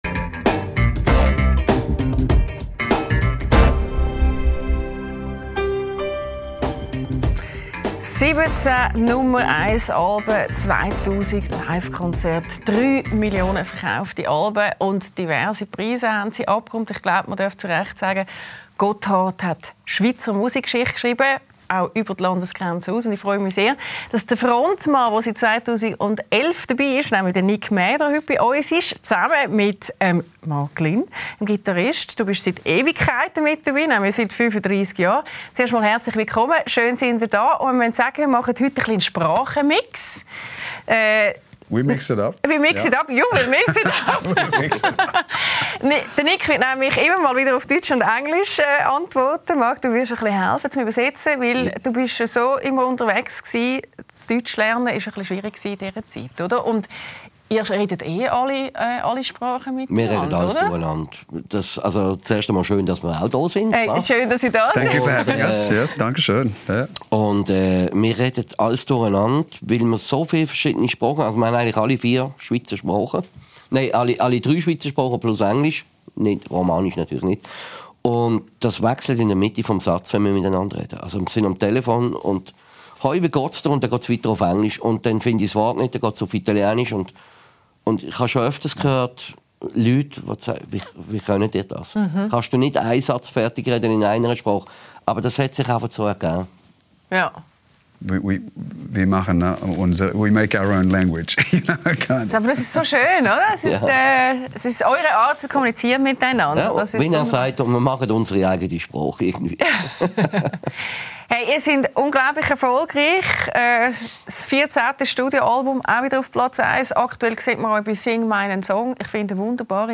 LÄSSER ⎥ Die Talkshow